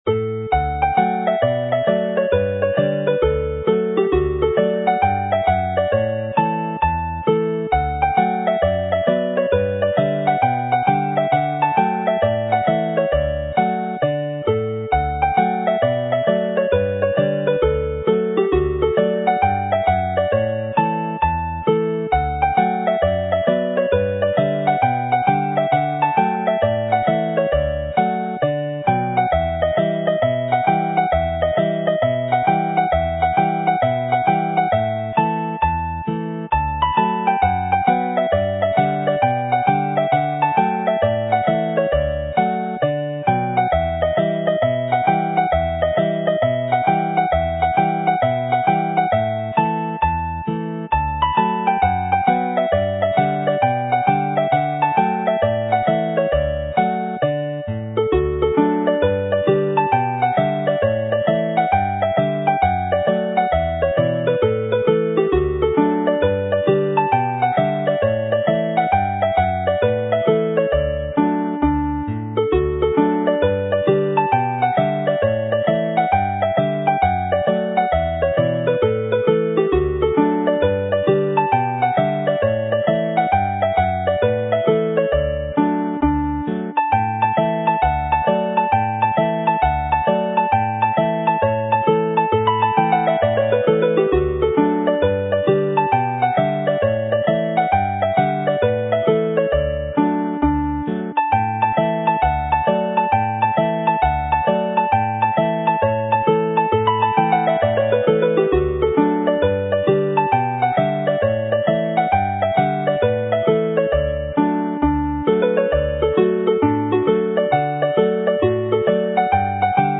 This set of hornpipes is one of the happiest you could hear